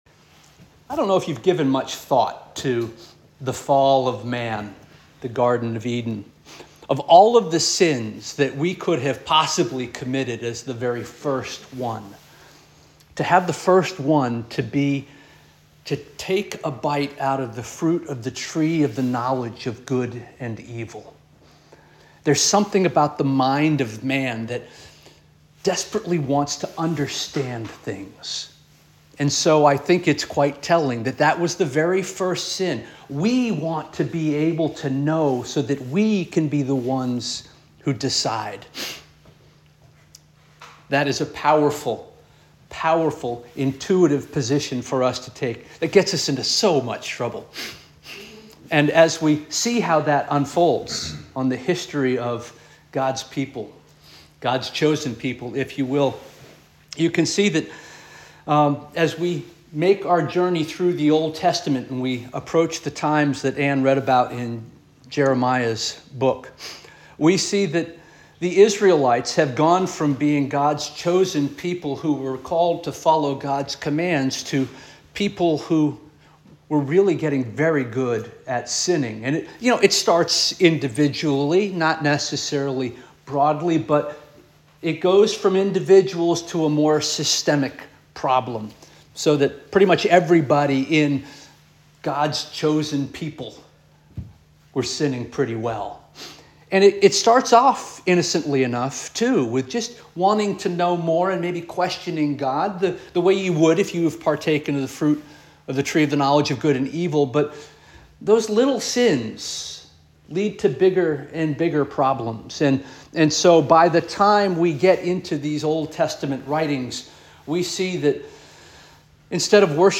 November 17 2024 Sermon - First Union African Baptist Church